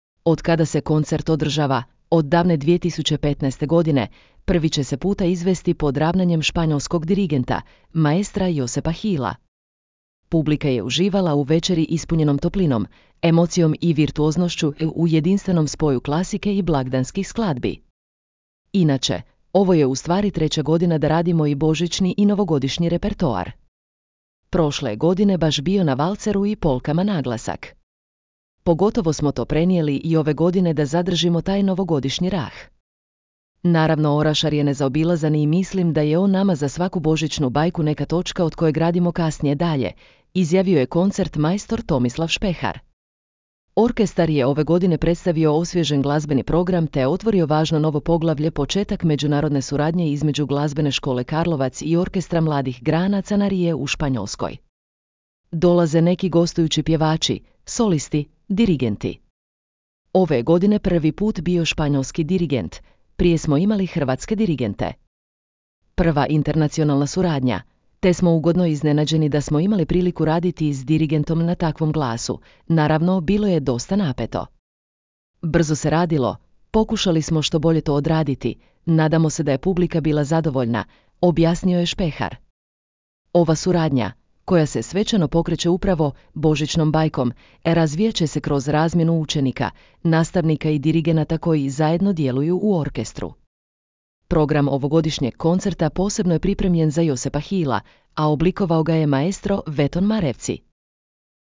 Publika je uživala u večeri ispunjenom toplinom, emocijom i virtuoznošću – u jedinstvenom spoju klasike i blagdanskih skladbi.